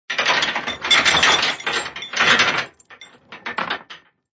Звуки якоря
Грохот цепей